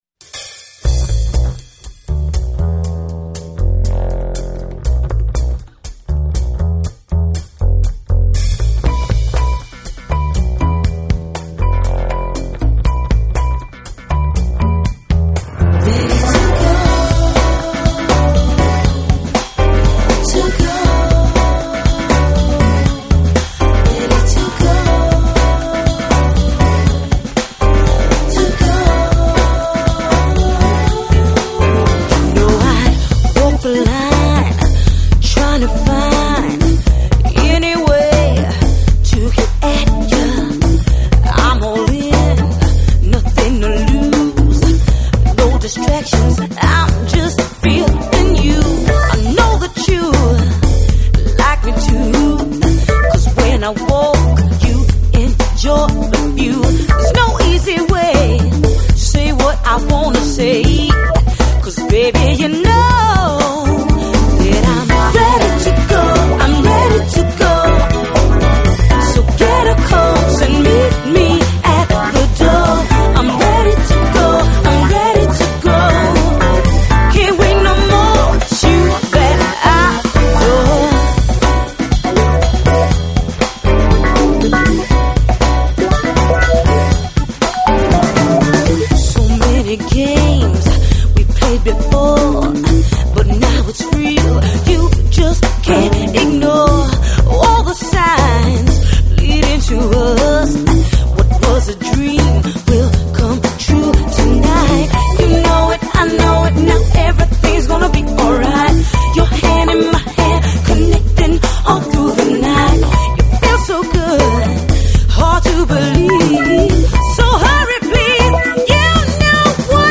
Genre: Jazz
Styles: Jazz, Crossover Jazz, Smooth Jazz, New Age
Bass (Acoustic)
Percussion
Piano, Arranger, Keyboards, Programming, Fender Rhodes
Sax (Alto)